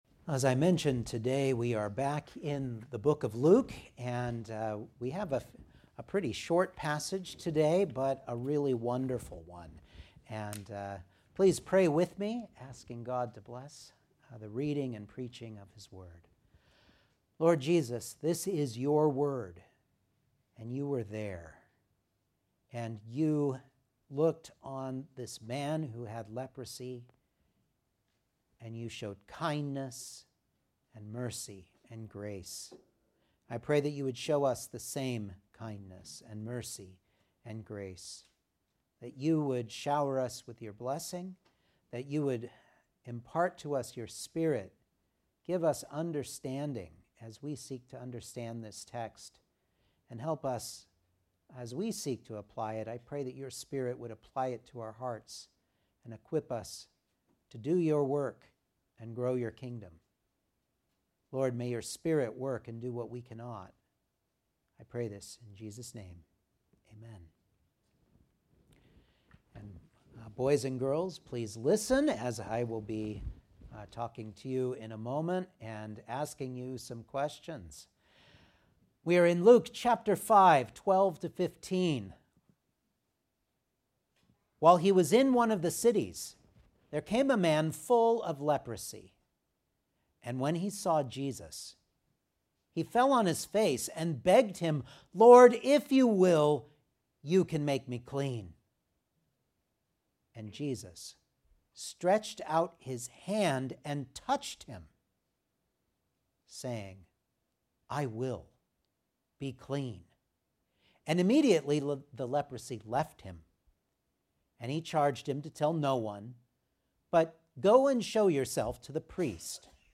Luke 5:12-15 Service Type: Sunday Morning Outline